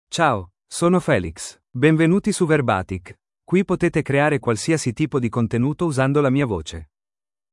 FelixMale Italian AI voice
Felix is a male AI voice for Italian (Italy).
Voice sample
Listen to Felix's male Italian voice.
Male
Felix delivers clear pronunciation with authentic Italy Italian intonation, making your content sound professionally produced.